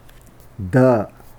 スピーカだぁ゜/だぁ（竹富方言）